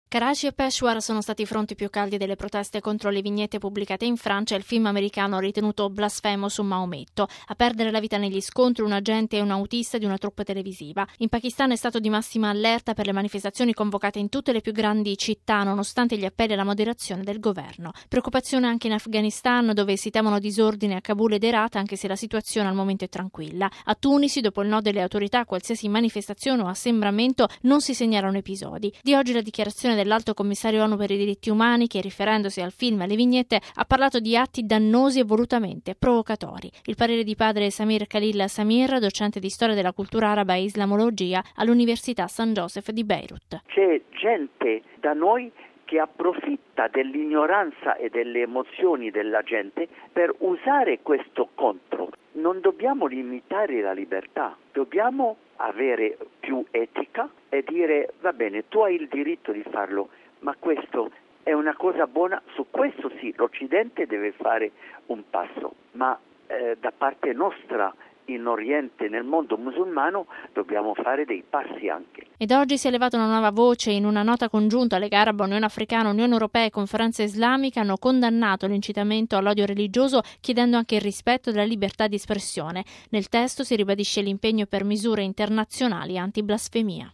Radiogiornale del 21/09/2012 - Radio Vaticana